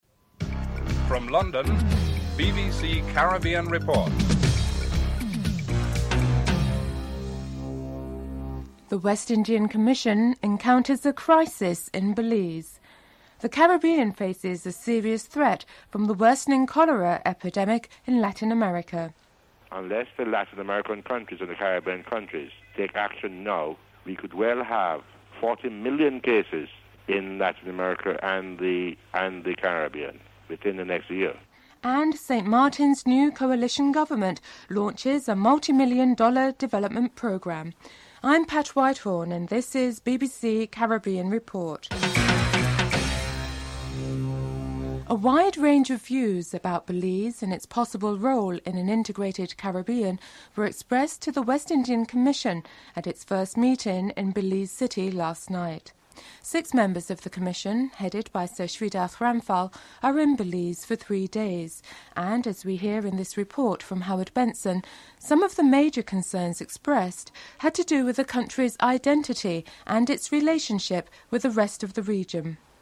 The British Broadcasting Corporation
1. Headlines (00:00-00:45)